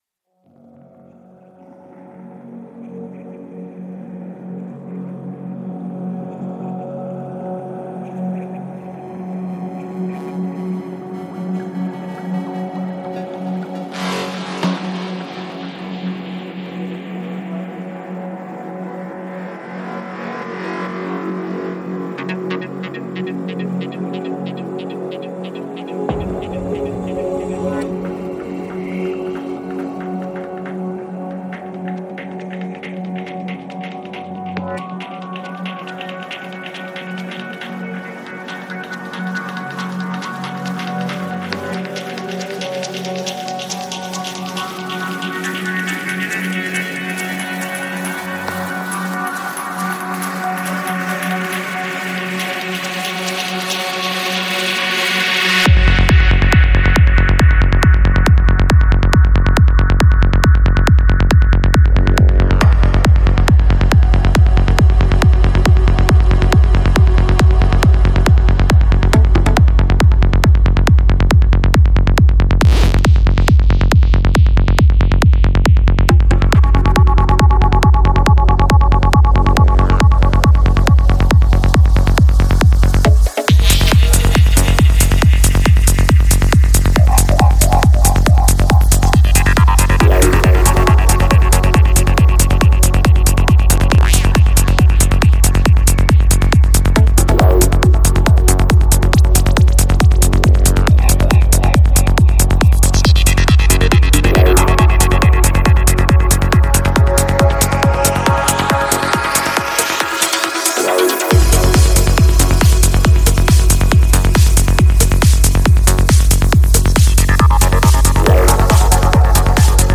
Жанр: Psy Trance